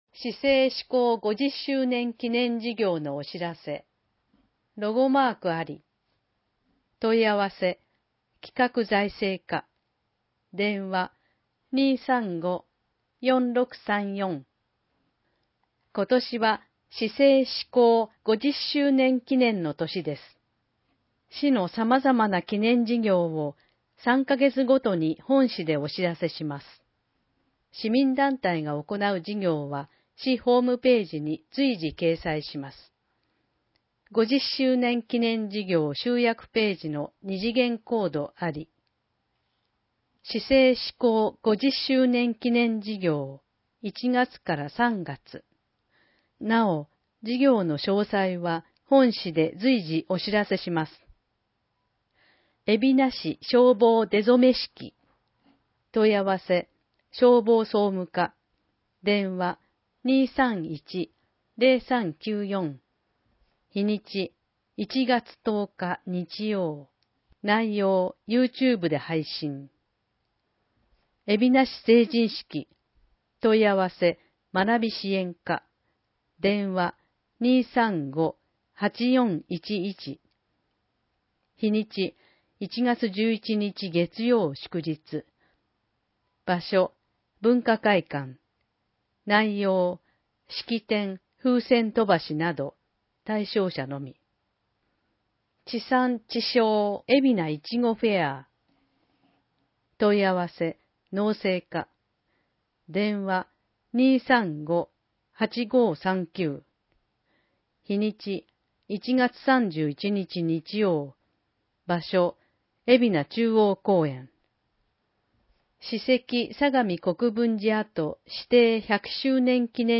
広報えびな 令和3年1月1日号（電子ブック） （外部リンク） PDF・音声版 ※音声版は、音声訳ボランティア「矢ぐるまの会」の協力により、同会が視覚障がい者の方のために作成したものを登載しています。